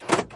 车辆 " 标致307汽车门锁
描述：标致307门锁远程
Tag: 开锁 汽车锁